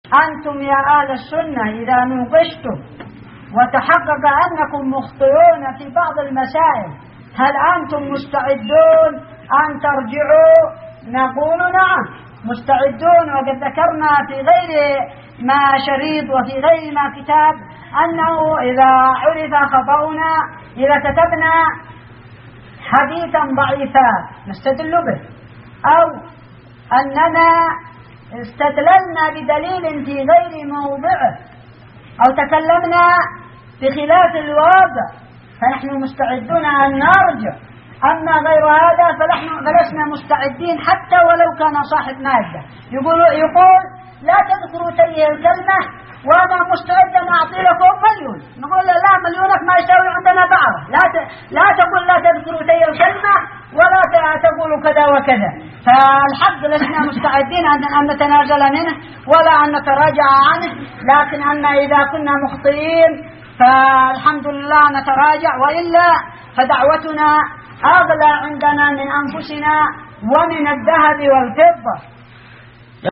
فتاوى